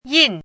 chinese-voice - 汉字语音库
yin4.mp3